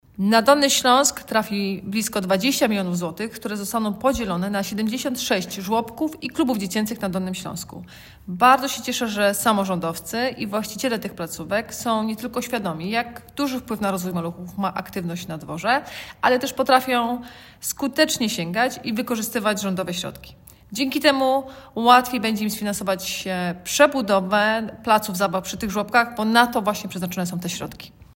Mówi Anna Żabska Wojewoda Dolnośląska.